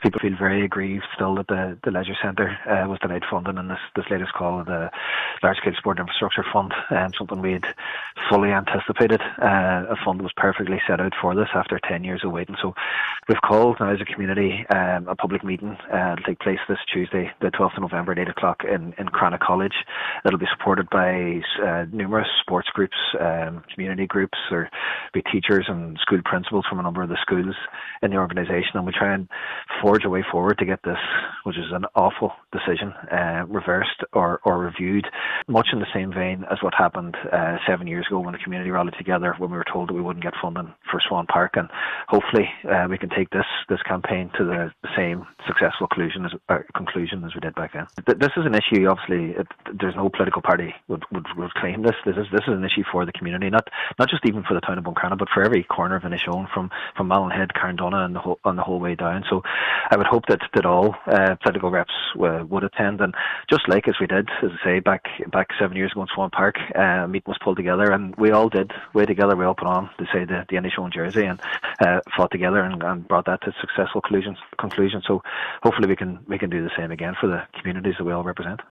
Inishowen MD Cathaoirleach Jack Murray wants public representatives of all parties to attend, saying this is very much a community issue rather than a political one………